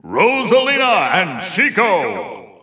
The announcer saying Rosalina & Luma's names in Korean releases of Super Smash Bros. 4 and Super Smash Bros. Ultimate.
Rosalina_&_Luma_Korean_Announcer_SSB4-SSBU.wav